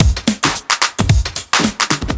OTG_TripSwingMixB_110b.wav